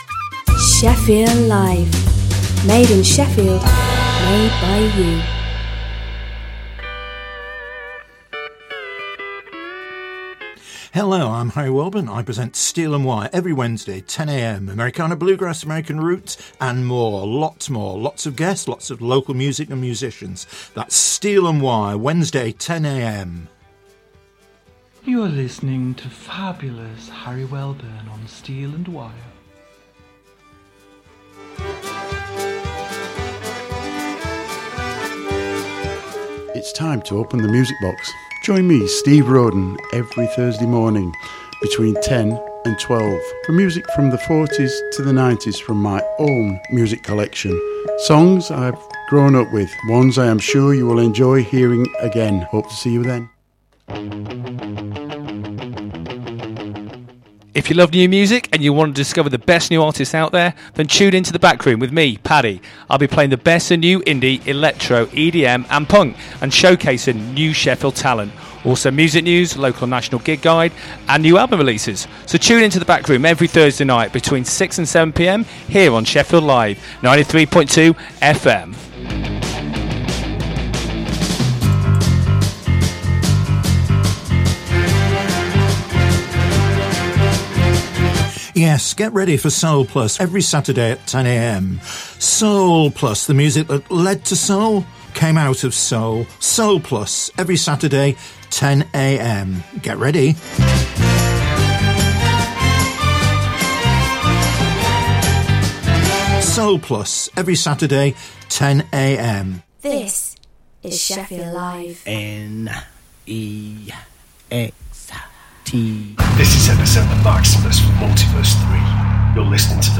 2 hours of the best popular classic and Prog rock music plus Gig and band info..